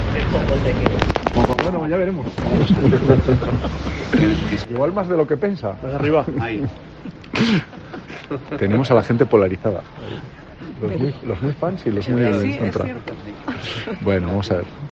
El candidato PSOE a la alcaldía de Logroño acudía a su cita en el Casco Antiguo.
Antes de atender a los medios de comunicación, frente a la puerta del Centro de Salud Rodríguez Paterna, una pareja pasaba muy cerca del candidato.
Sin pararse pero con voz alta y clara, el hombre le decía al candidato “qué poco te queda”. Pablo Hermoso de Mendoza reaccionaba con rapidez, contundente pero con un tono reposado y entre risas: “Ya veremos, igual más de lo que piensa”, respondía.
Pablo Hermoso de Mendoza intervenía muy cerca de las obras en el antiguo Colegio San Bernabé y agradecía a los obreros la breve parada en su trabajo para evitar ruidos durante esos minutos.